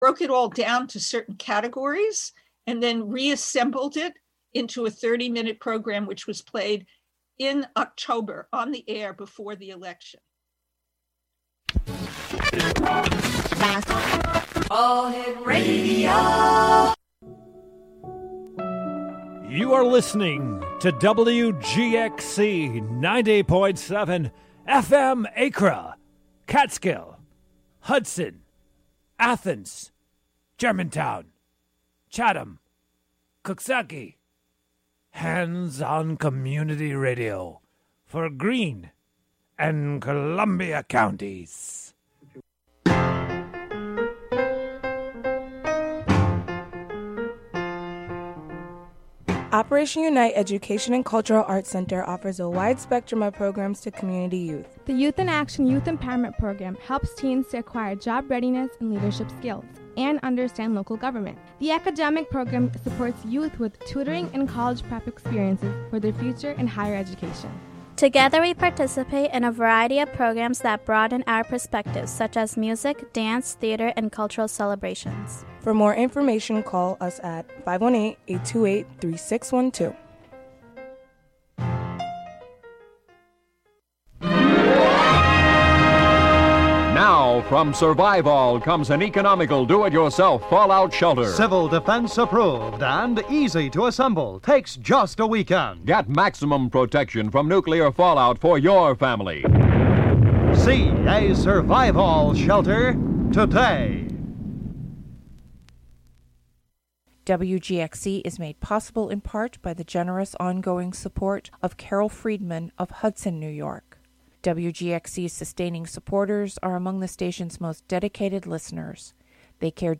Foraging Ahead is a monthly program featuring music and interviews from Dutchess County resident.